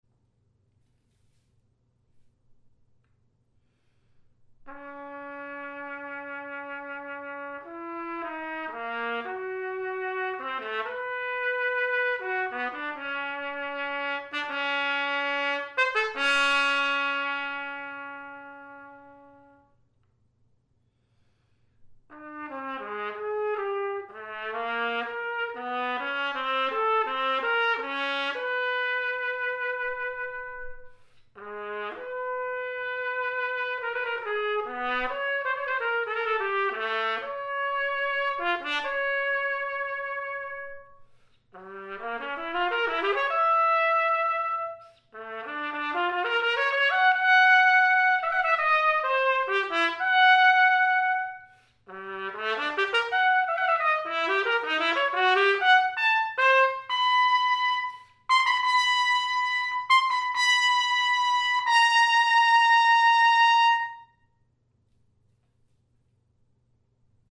For Bb or C trumpet and piano (1996)
The following clips were recorded on an Eclipse C trumpet.
mvt. 1 cadenza